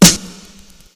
kits/RZA/Snares/WTC_SNR (8).wav at 32ed3054e8f0d31248a29e788f53465e3ccbe498